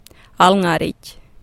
ŋal ŋariɟ